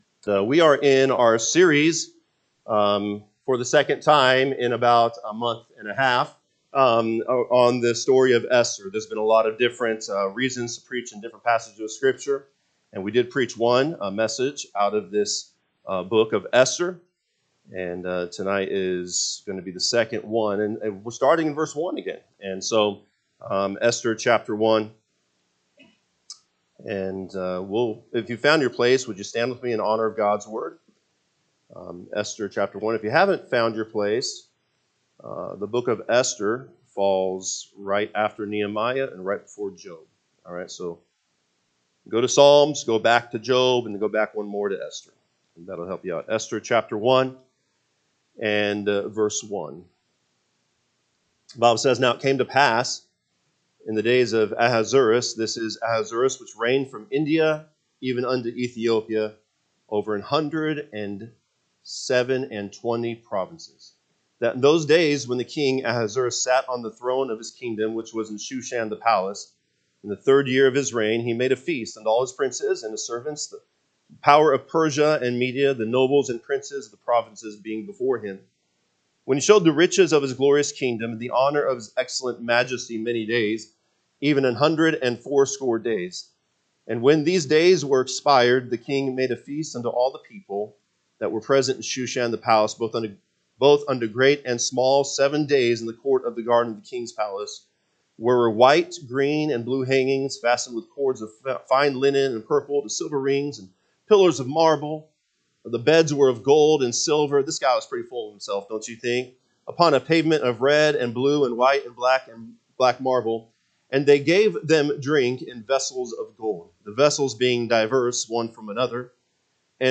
May 25, 2025 pm Service Esther 1:1-9 (KJB) 1 Now it came to pass in the days of Ahasuerus, (this is Ahasuerus which reigned, from India even unto Ethiopia, over an hundred and seven and twenty…